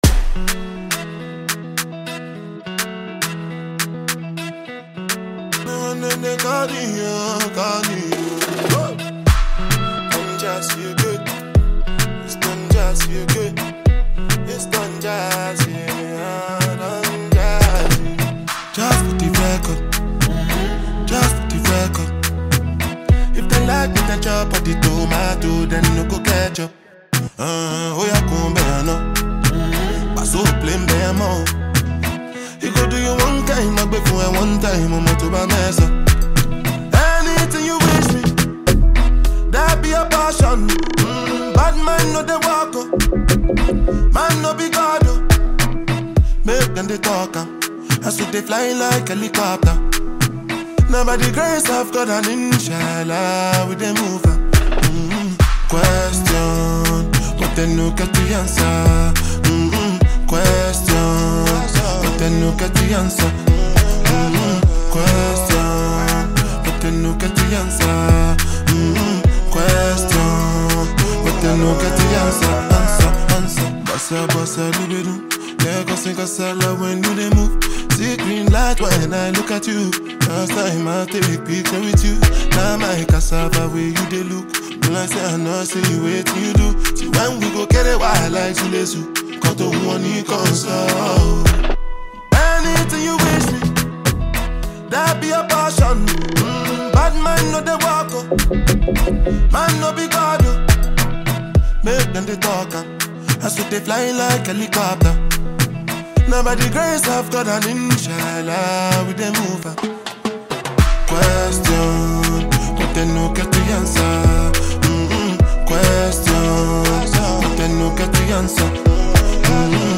afro-fusion